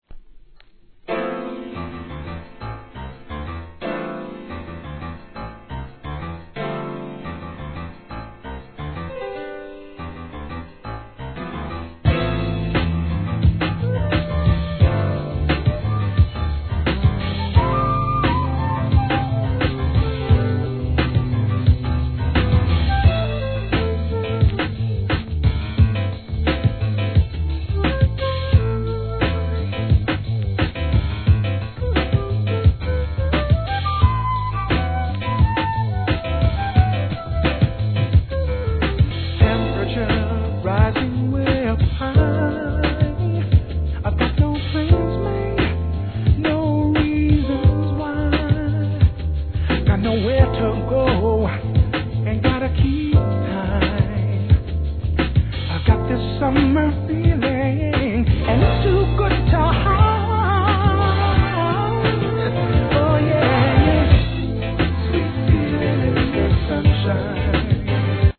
メロ〜UK SOUL !!